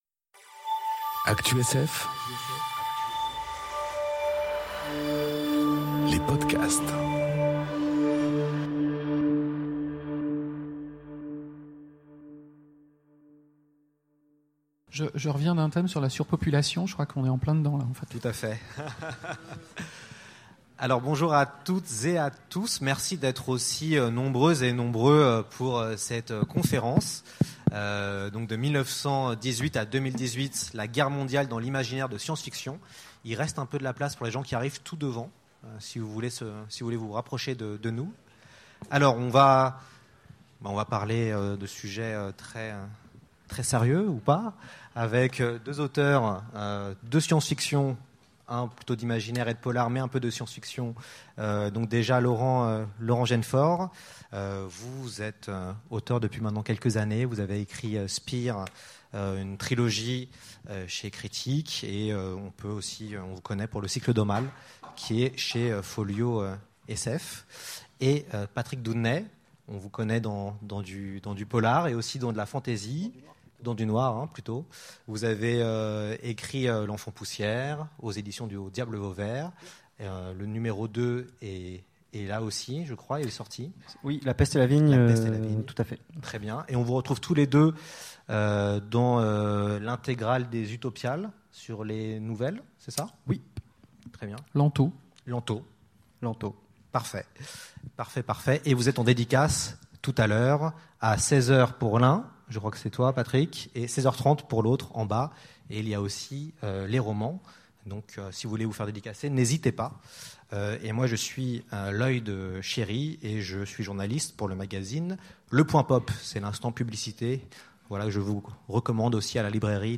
Conférence De 1918 à 2018 : La Guerre Mondiale dans l’imaginaire de sf enregistrée aux Utopiales 2018